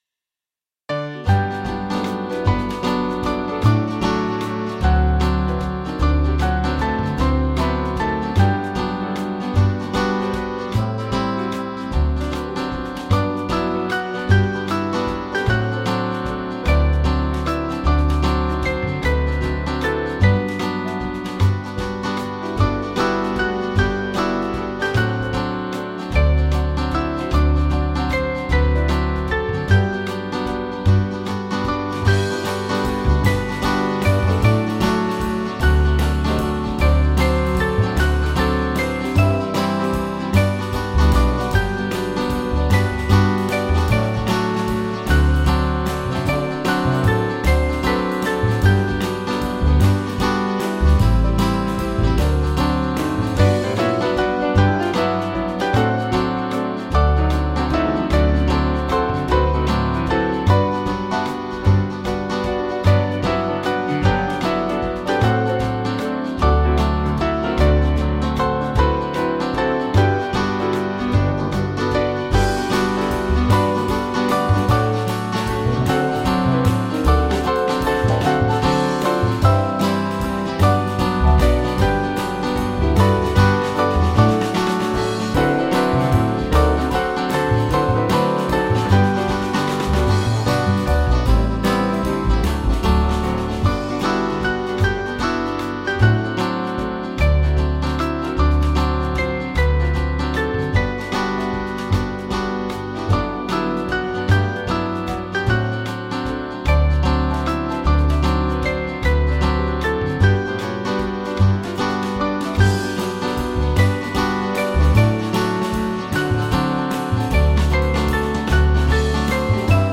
Swing Band
(CM)   4/G